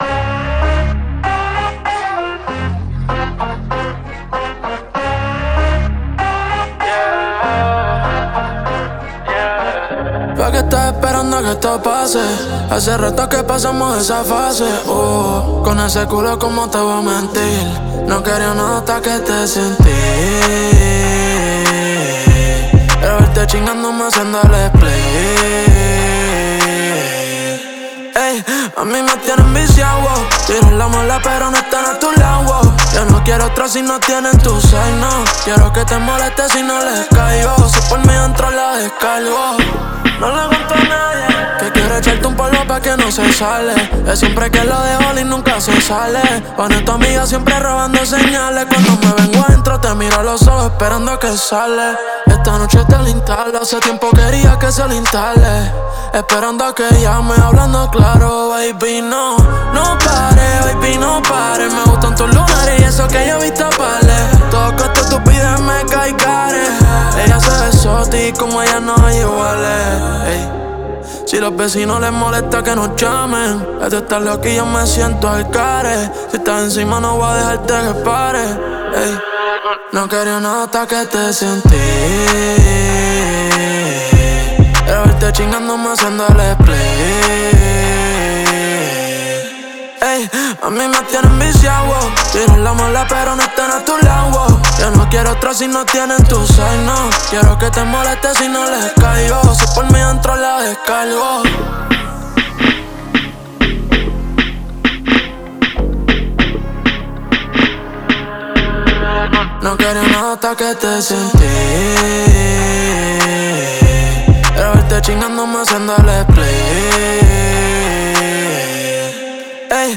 Латин-поп